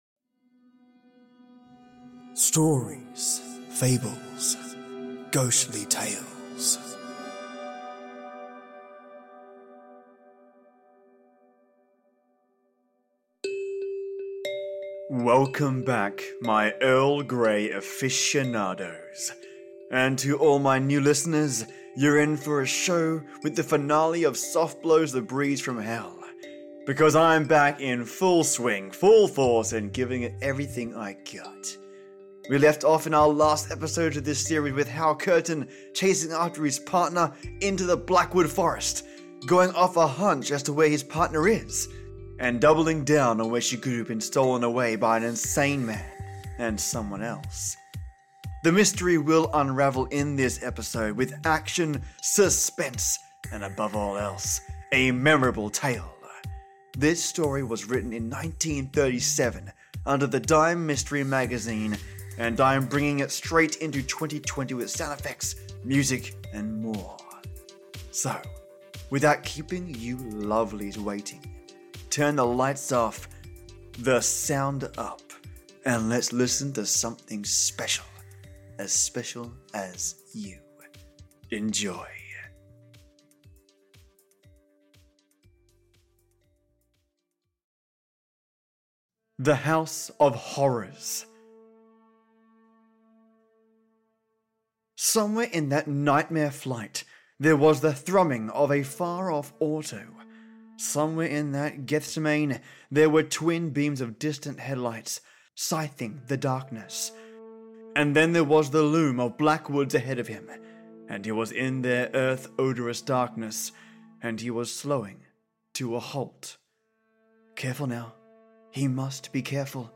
The mystery will unravel in this episode, with action, suspense, and above all else, a memorable tale. This story was written in 1937 under the Dime Mystery Magazine, and I’m bringing straight into the 2020 with sound effects, music, and more.